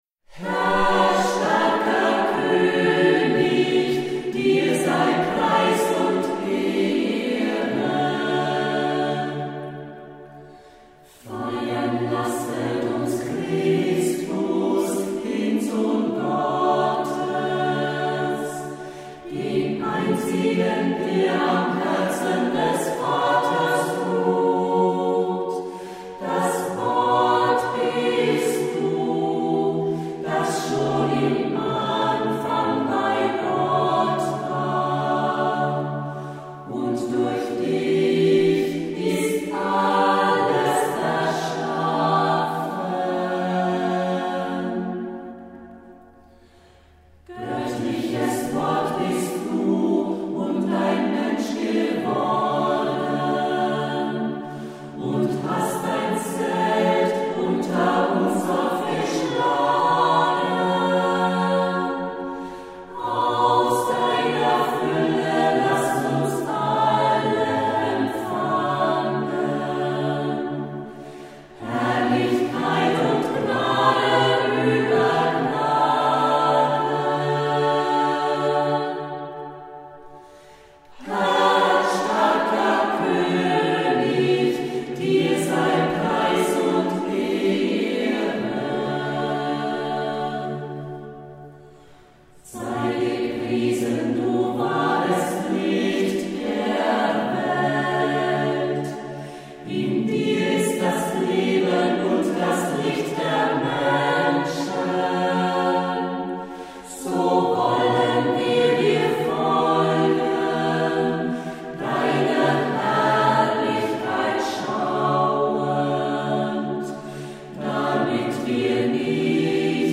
1 Instrumental